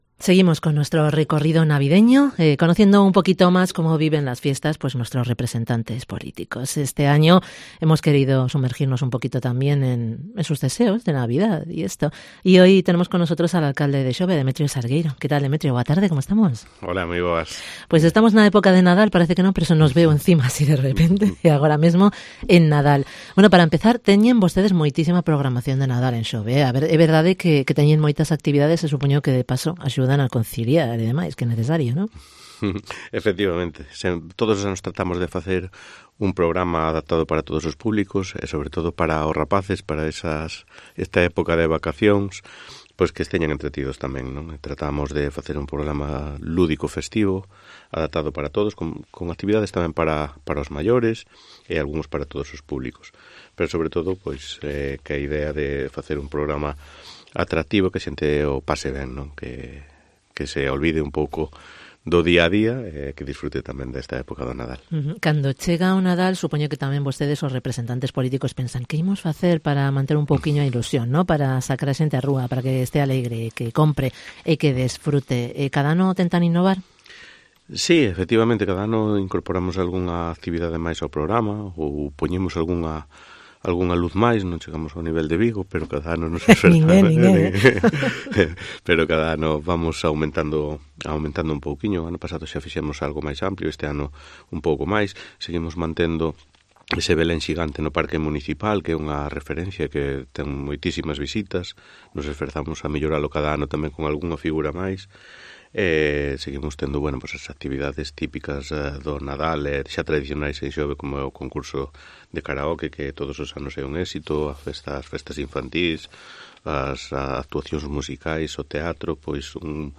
ENTREVISTA con Demetrio Salgueiro, alcalde de Xove